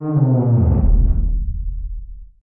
MOAN EL 06.wav